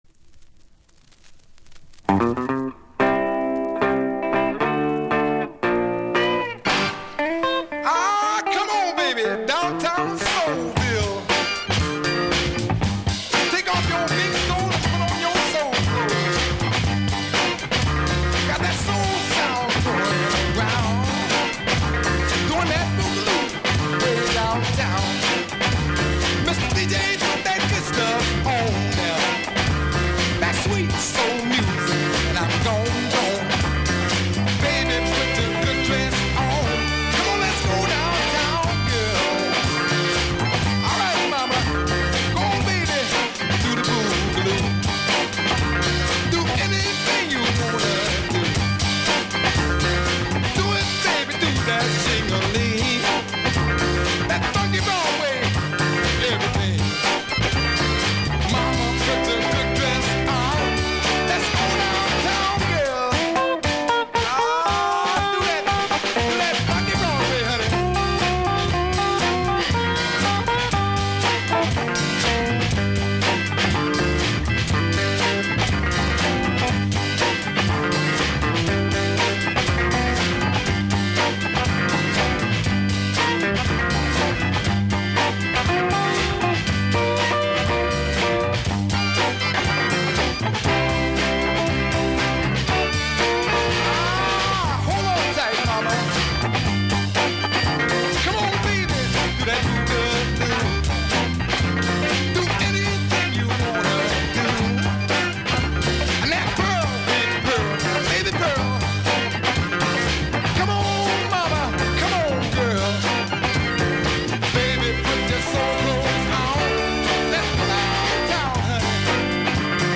Music behind DJ